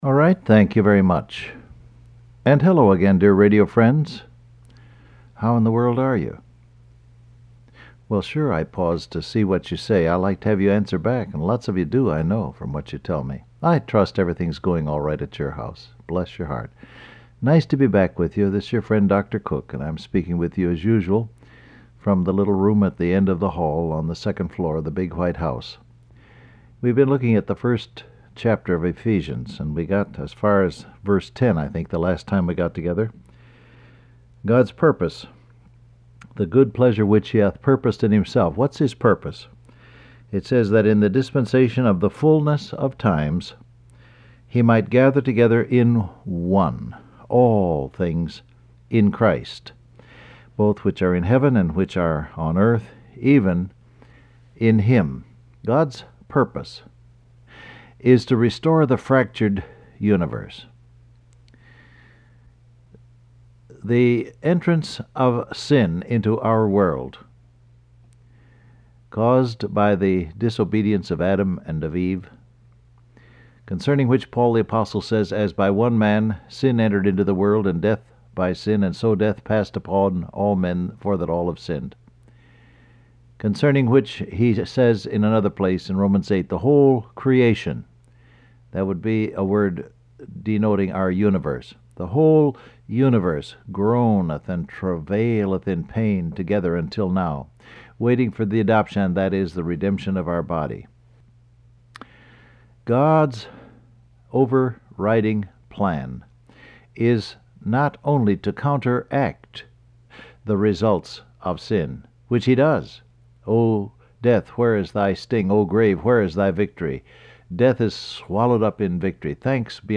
Broadcast